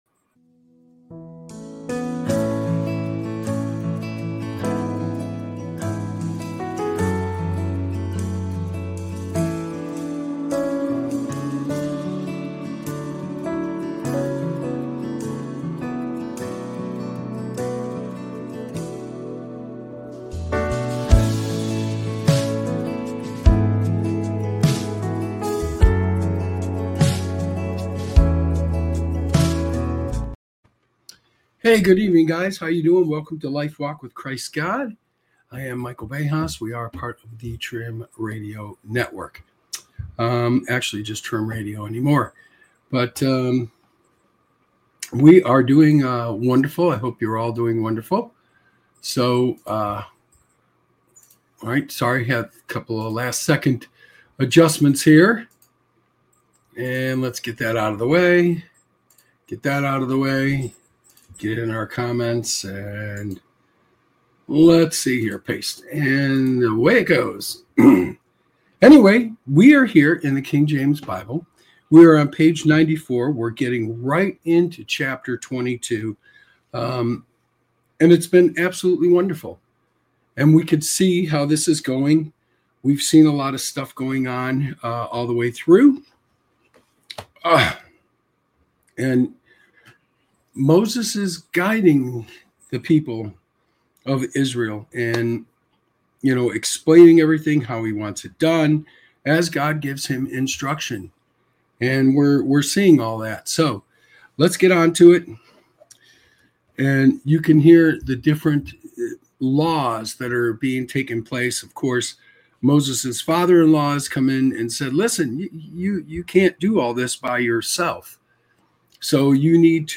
This show offers a reading into the teachings of Jesus Christ, providing insights into the Bible. Through engaging readings, heartfelt testimonies, and inspiring messages, Lifewalk with Christ God aims to: Strengthen faith: Explore the power of prayer, worship, and devotion.